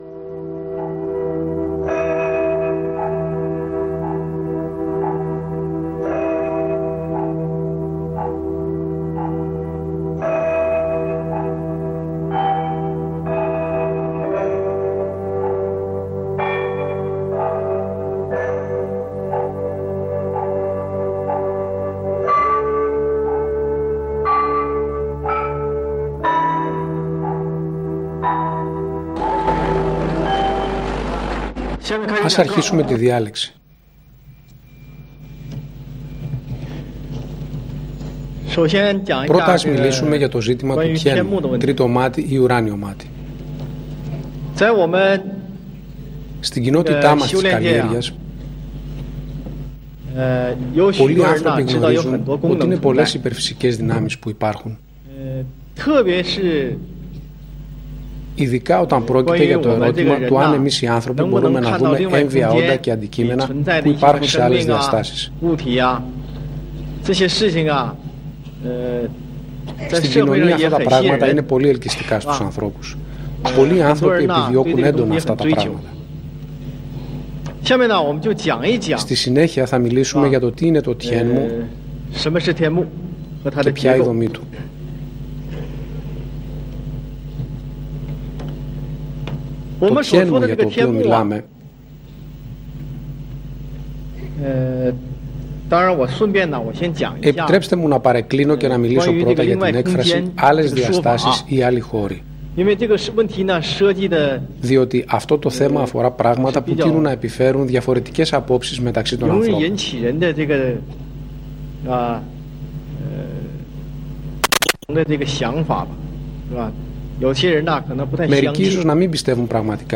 Διάλεξη 1